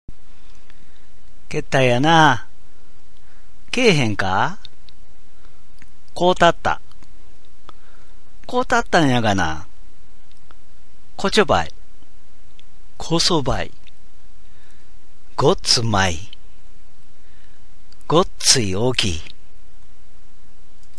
私は河内育ちの大阪弁を話しますが、聞いて分かりますか
引き続いて、大阪弁の独特の言葉をピックアップして、音声を録音しています。